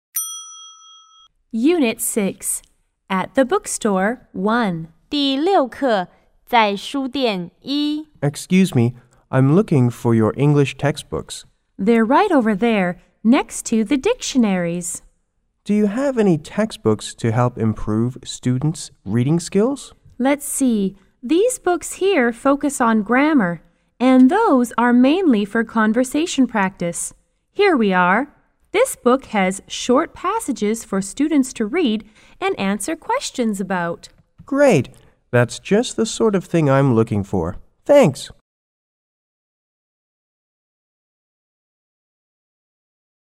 S= Student C= Clerk